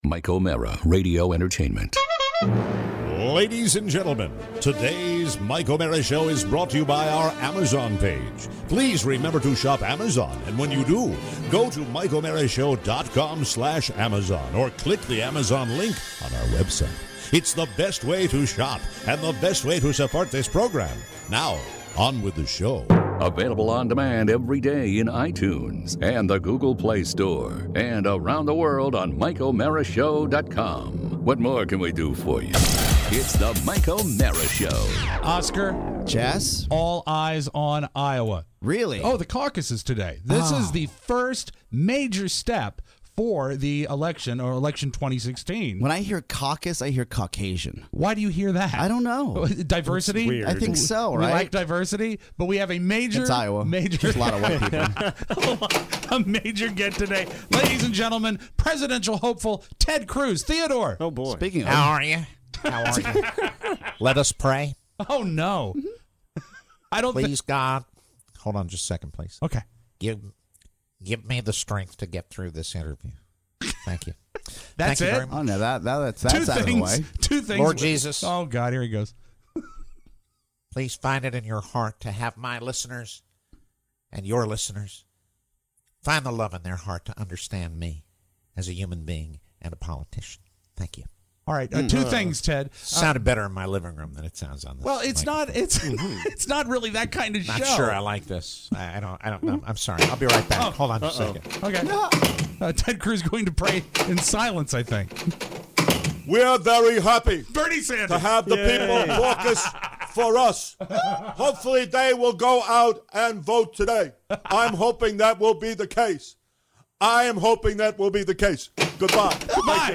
So many impressions!